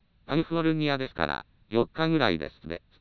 以下に本システムで作成された合成音声を状態継続長の符号化手法別にあげる